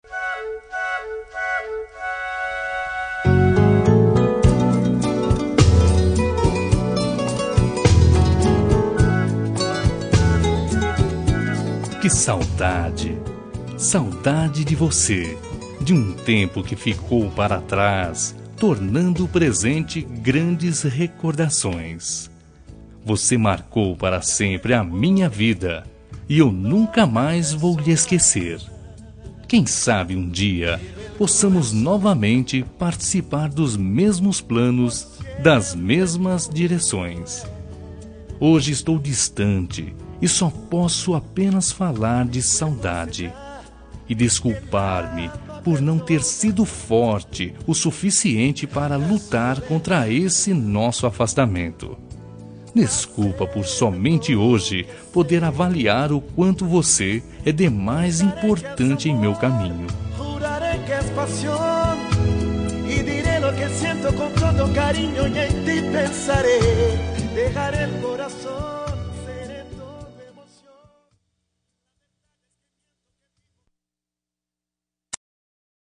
Telemensagem de Saudades – Voz Masculina – Cód: 449
449-saudades-masc-1.m4a